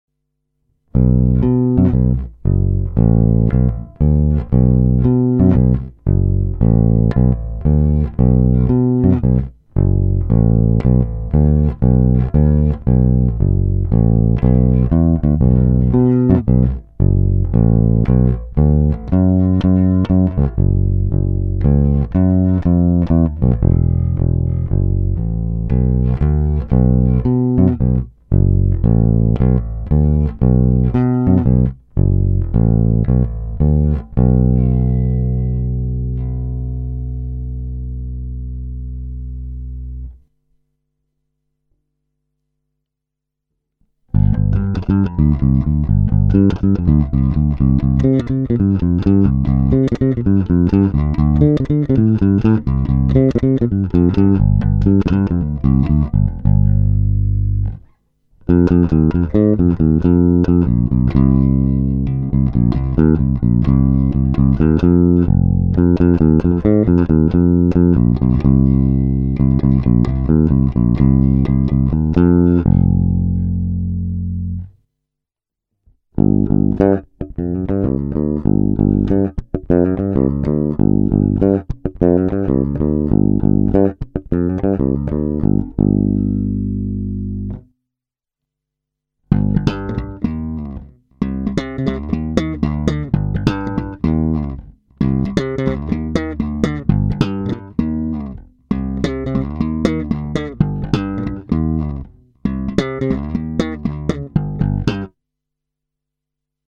Nahrávka s hlazenými strunami Thomastik, čili ne moc reprezentativní, roundy by byly lepší. Hráno na oba snímače, nepatrně přidané basy a středy. Pořadí: krkový snímač - dvě ukázky na oba snímače - kobylkový snímač - slap na oba snímače.